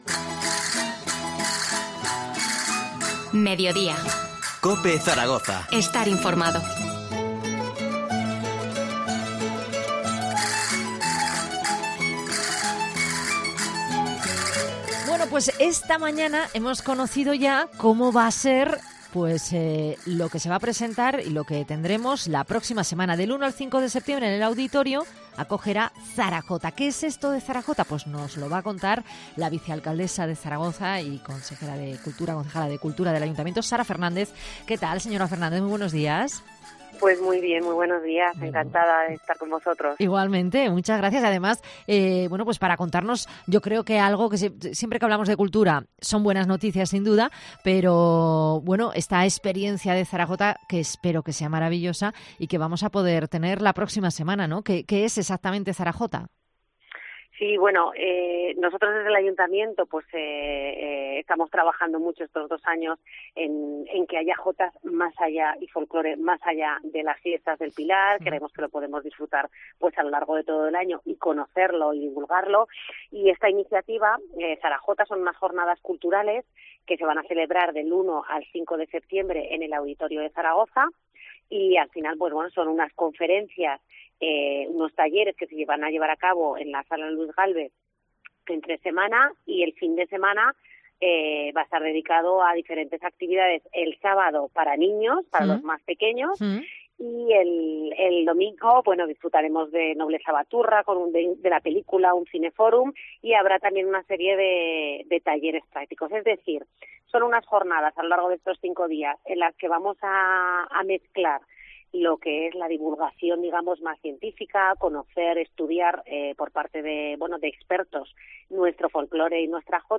Entrevista a la vicealcaldesa Sara Fernández sobre las jornadas 'ZaraJota'.